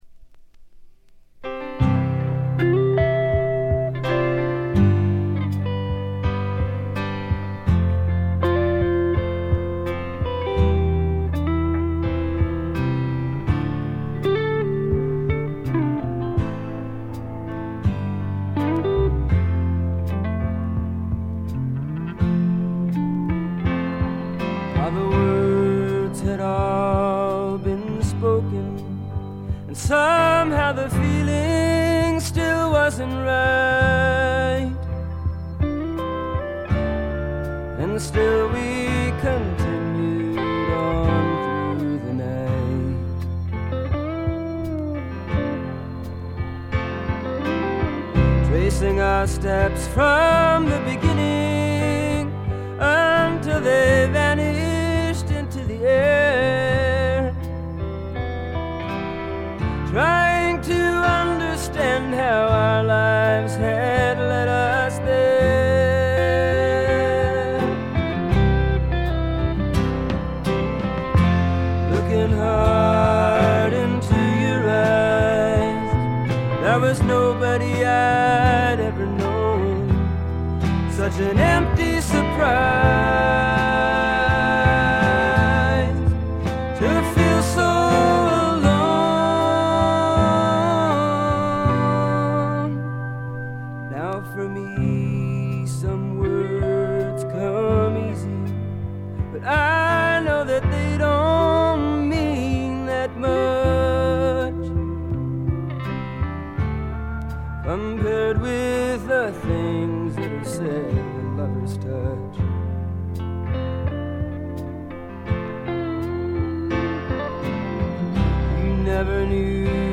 ほとんどノイズ感無し。
まるでバンド名義の作品のようなグルーヴ感がたまらないです。
試聴曲は現品からの取り込み音源です。